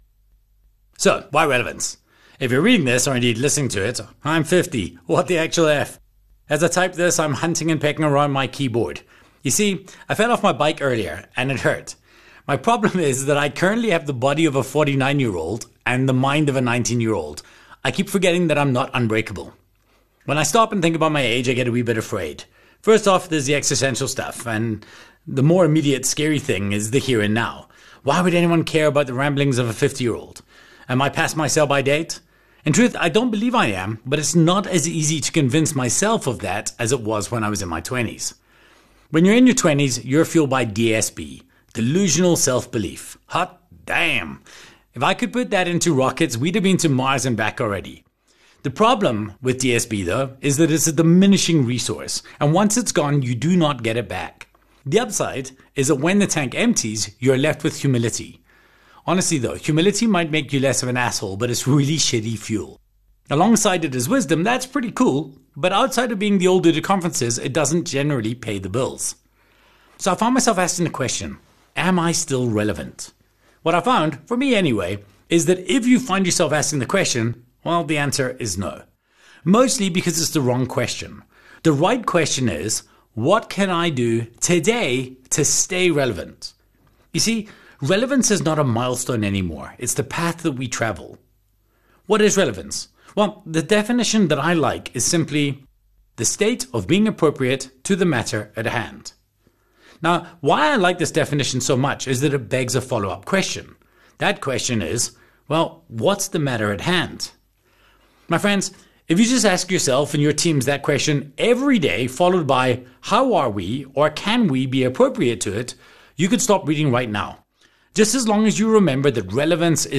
Audiobook Samples from Solid Gold